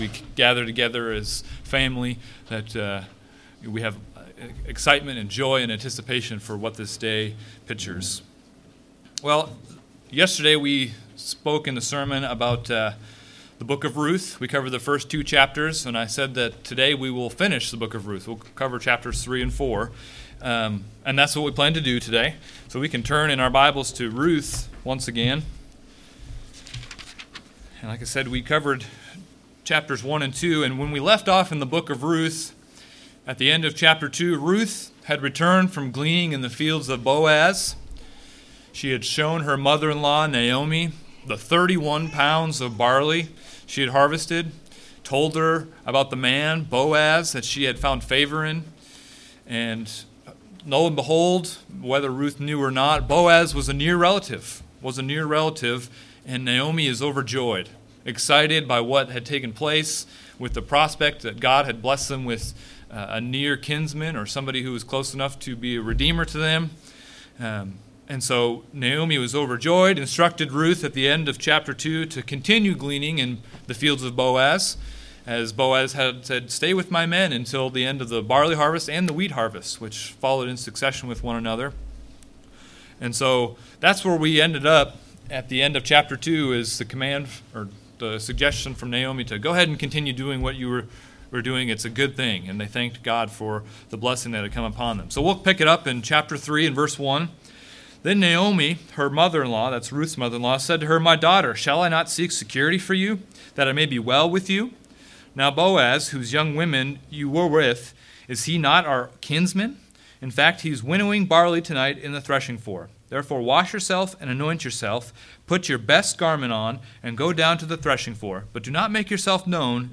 In this sermon, the speaker goes through a verse by verse reading of the last two chapters of the book of Ruth and expounds on lessons we can learn.